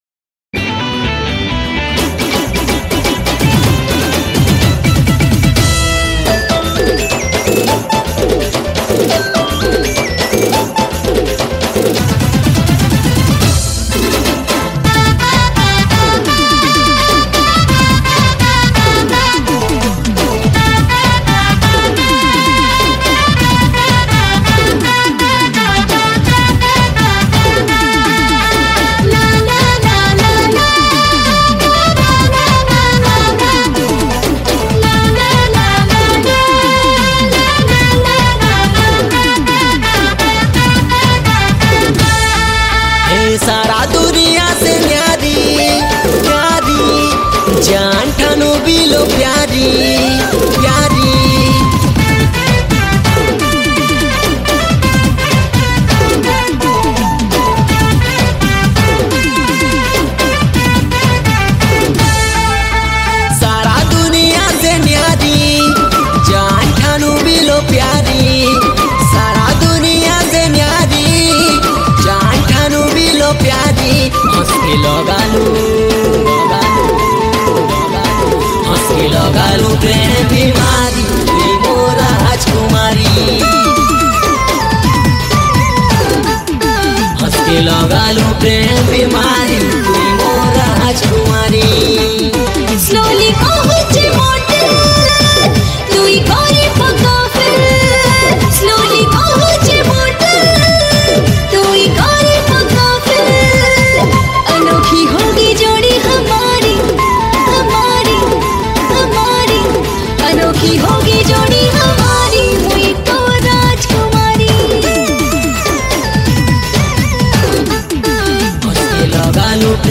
Sambalpuri Song